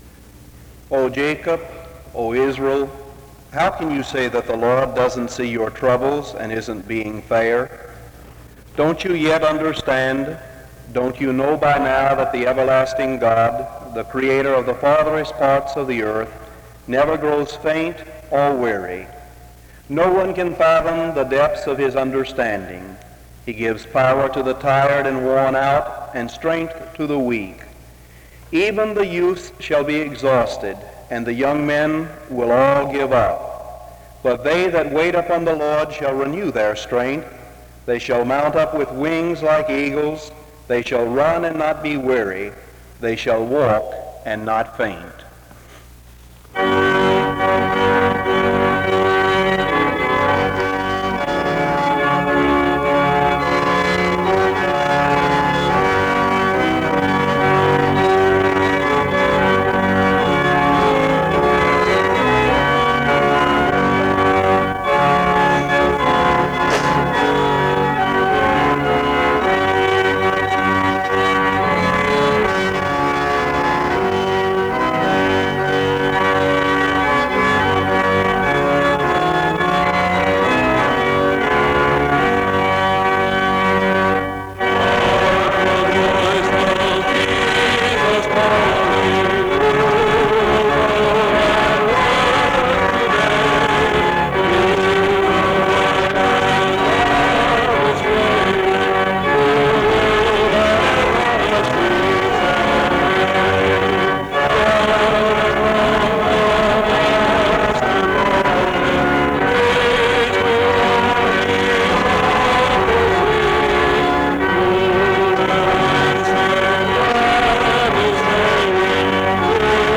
SEBTS Chapel and Special Event Recordings - 1970s